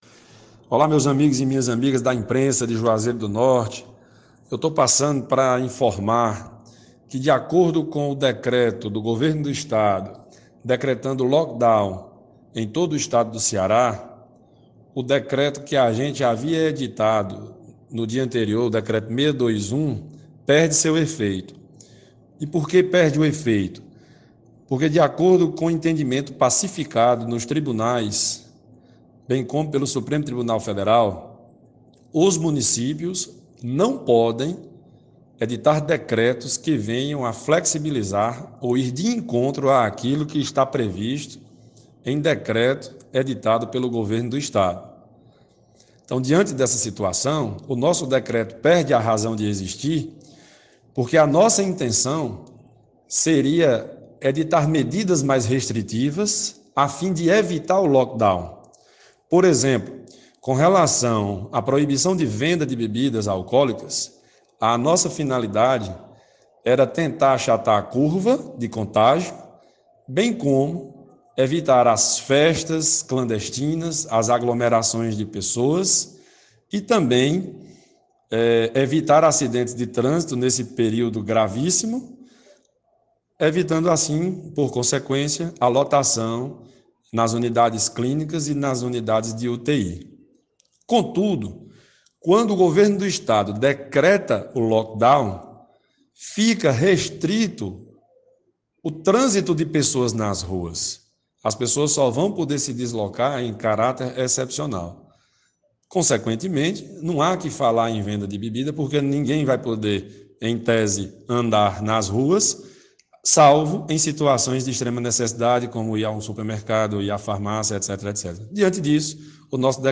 Com este novo decreto do Governo do Estado, o decreto de Juazeiro do Norte que entraria em vigor nesta sexta-feira (12) perdeu o efeito. Escute o que prefeito Glêdson Bezerra (Podemos) tem a dizer sobre esse assunto: